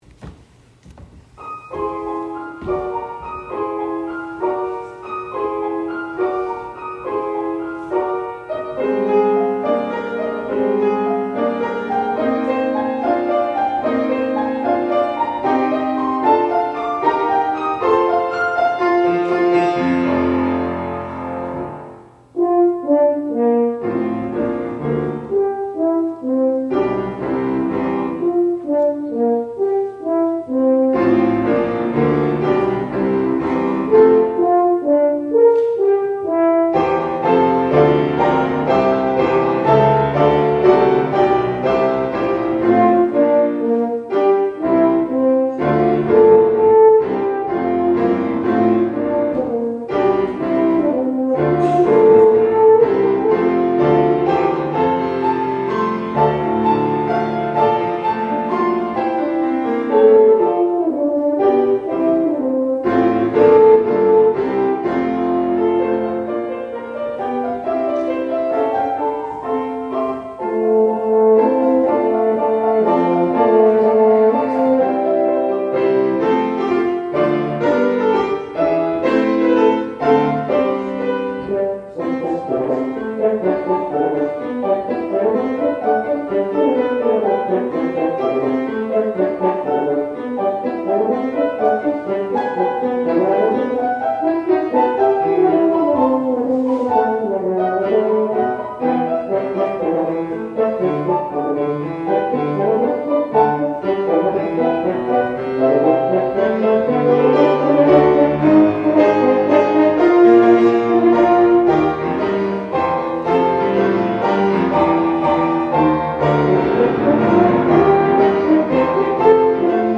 Offertory